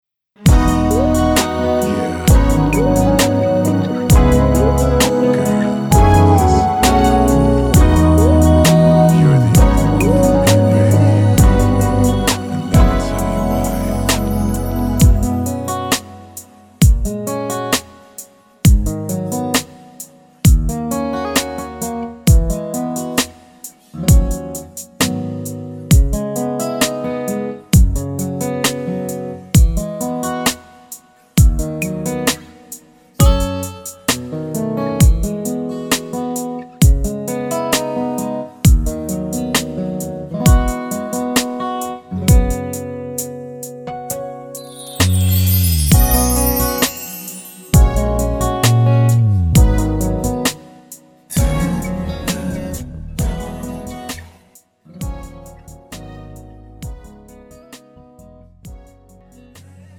음정 코러스 -1키
장르 축가 구분 Pro MR